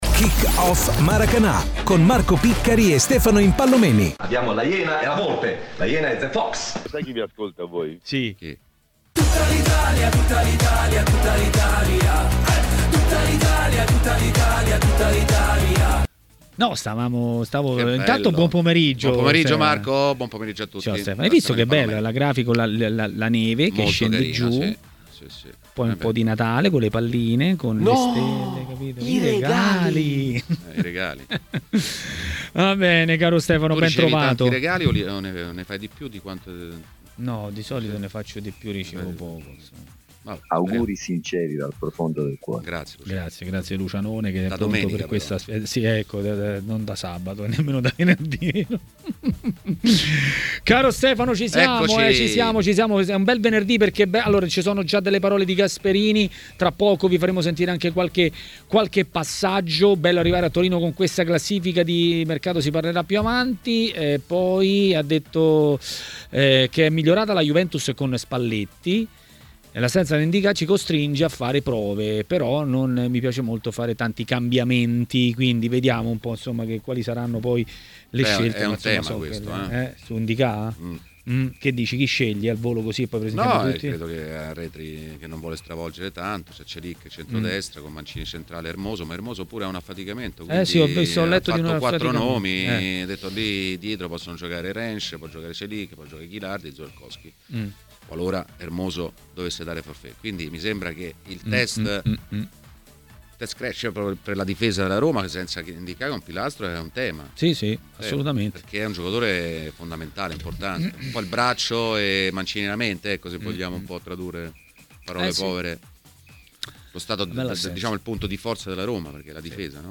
è stato ospite a Maracana nel pomeriggio di Tmw Radio.